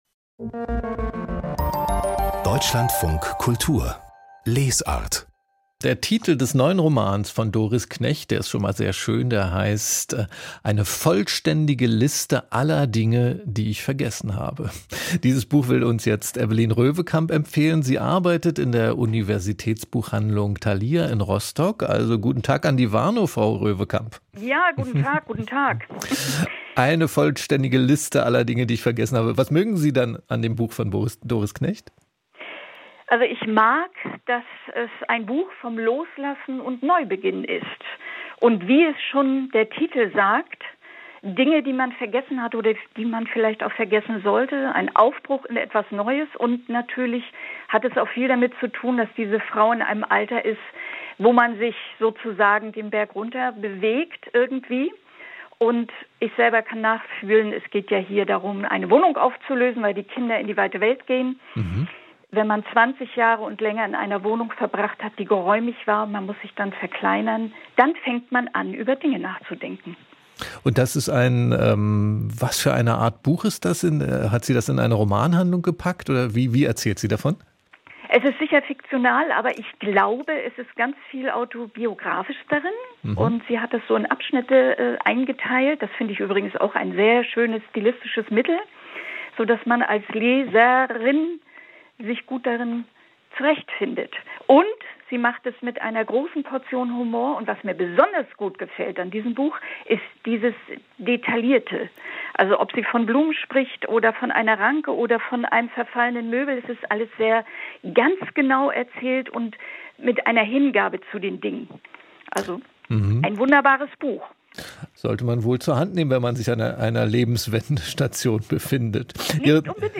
Buchhändlergespräch: Universitätsbuchhandlung Thalia aus Rostock